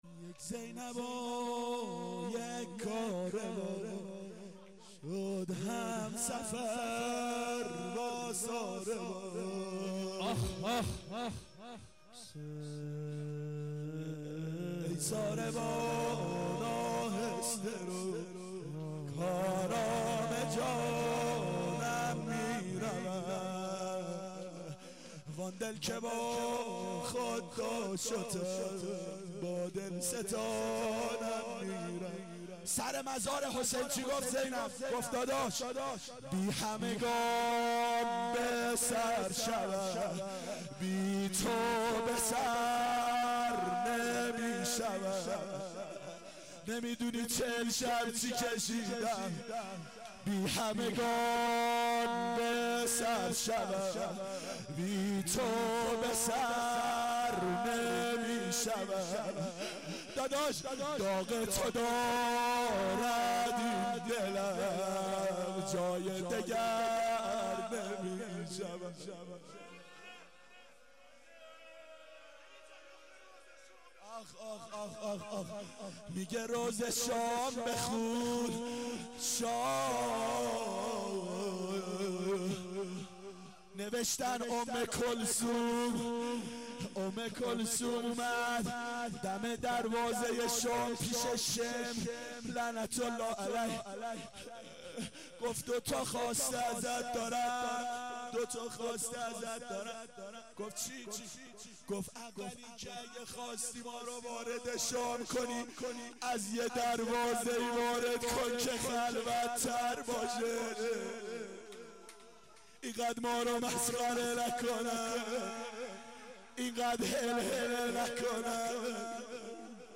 یک زینب و - روضه
اربعین 91 هیئت متوسلین به امیرالمؤمنین حضرت علی علیه السلام